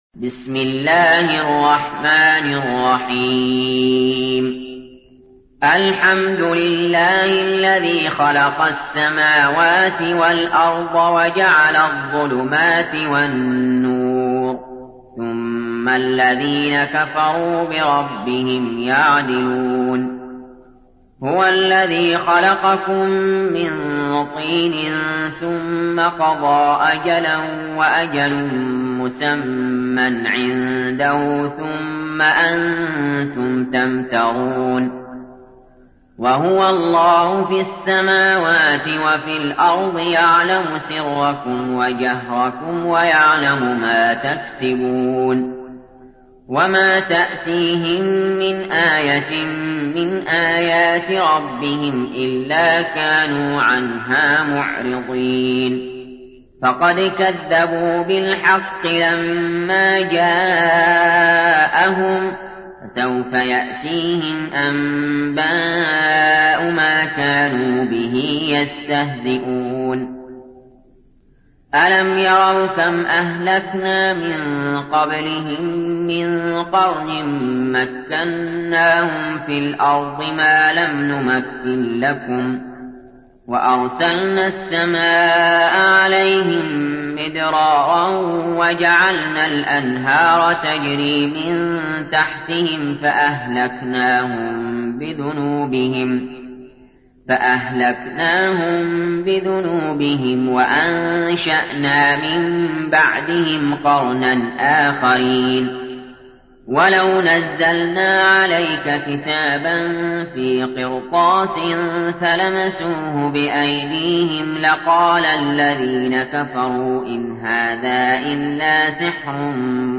تحميل : 6. سورة الأنعام / القارئ علي جابر / القرآن الكريم / موقع يا حسين